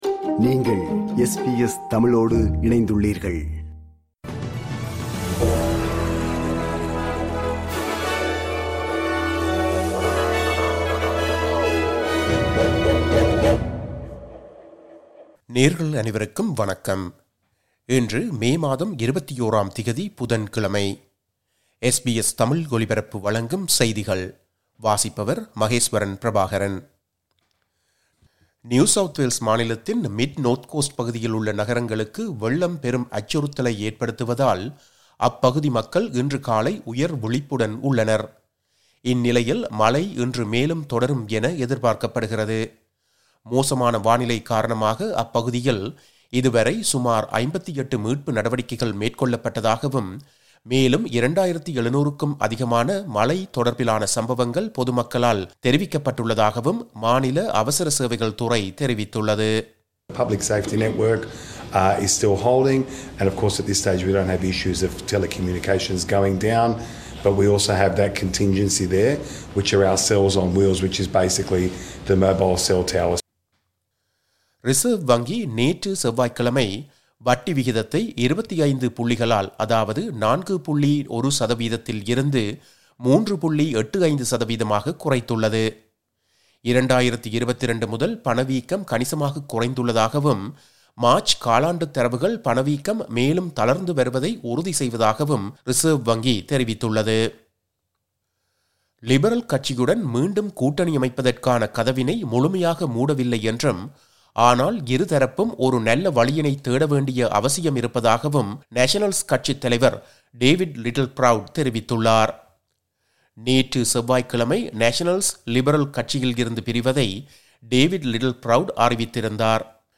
SBS தமிழ் ஒலிபரப்பின் இன்றைய (புதன்கிழமை 21/05/2025) செய்திகள்.